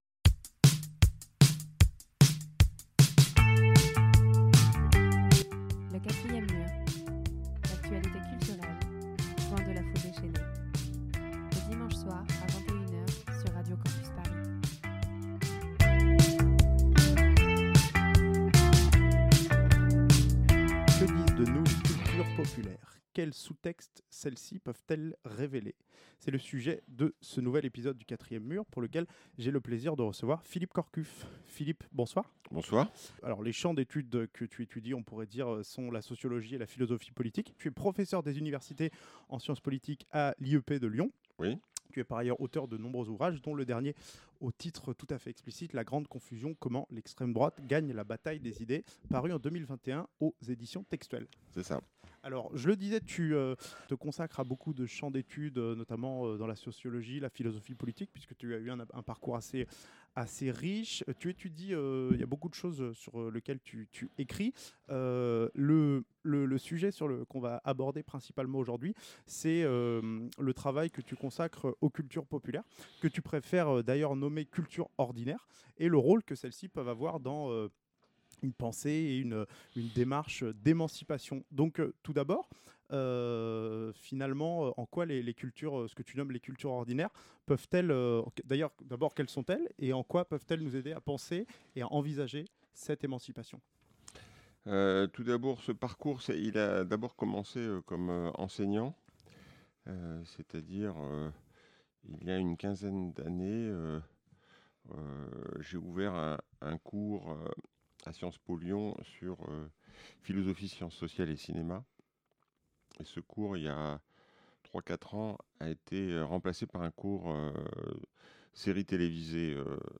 Dans cette riche heure d'interview, il nous expose en quoi les séries télévisées ou les chansons peuvent nous aider à nous émanciper.